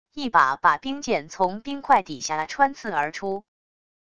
一把把冰剑从冰块底下穿刺而出wav音频